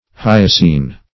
hyacine - definition of hyacine - synonyms, pronunciation, spelling from Free Dictionary Search Result for " hyacine" : The Collaborative International Dictionary of English v.0.48: Hyacine \Hy"a*cine\, n. A hyacinth.